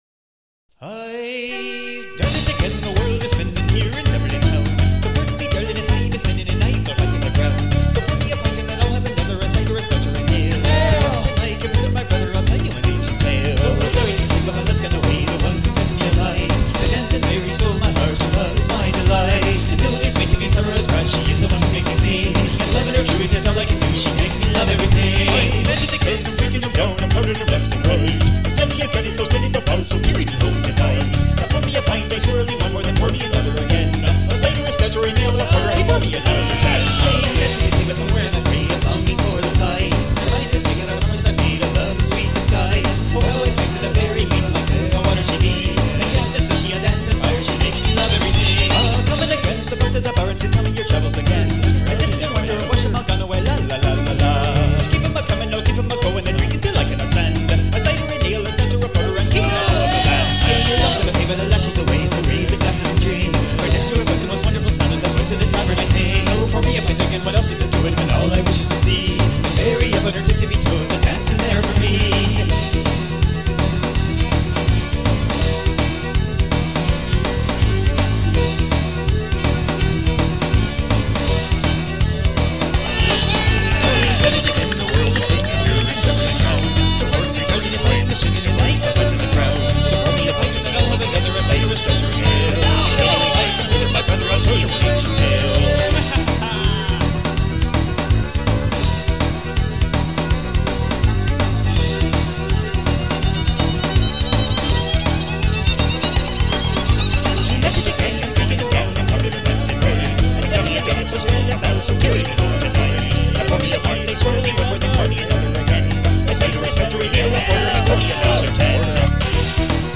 super secret special hidden awesomely good rough draft (not in a bottle) tunes in the works!